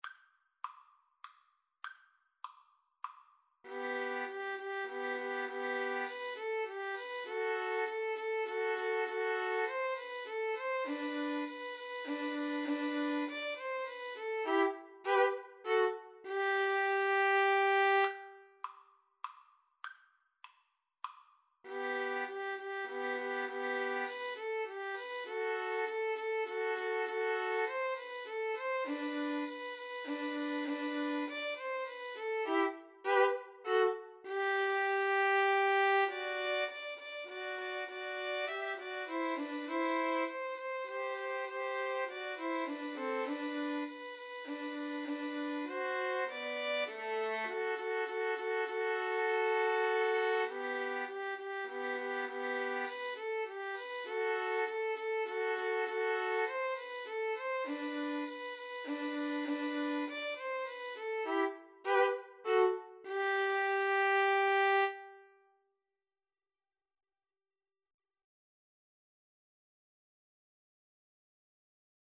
Allegro moderato (View more music marked Allegro)
G major (Sounding Pitch) (View more G major Music for Violin Trio )
3/4 (View more 3/4 Music)